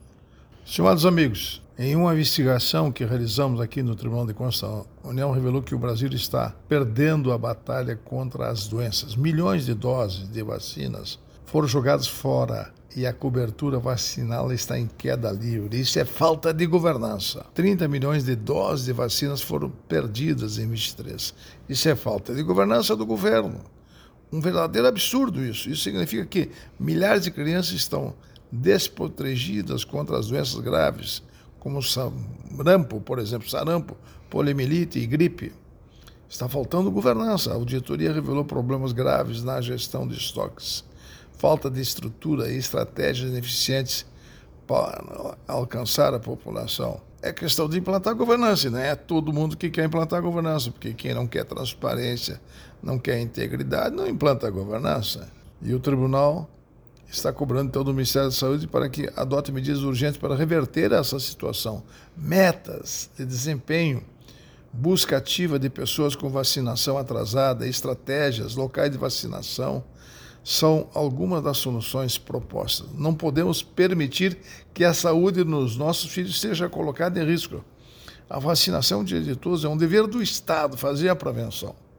Comentário de Augusto Nardes, ministro do Tribunal de Contas da União.